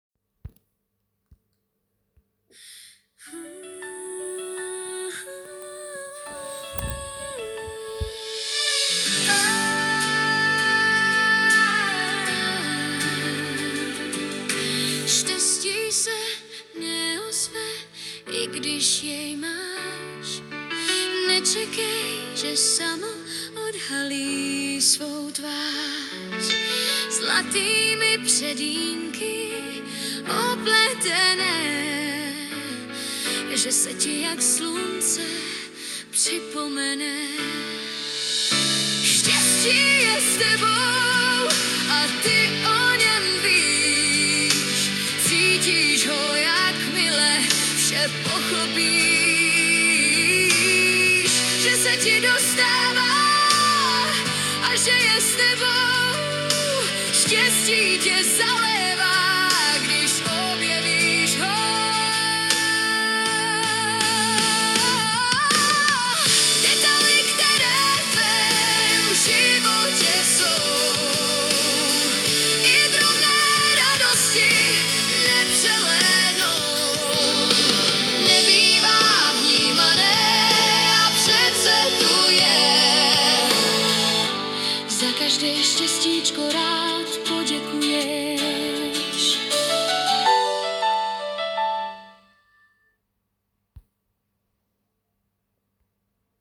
Hudba a zpěv AI, text můj